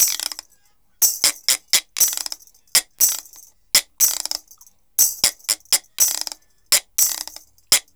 124-PERC1.wav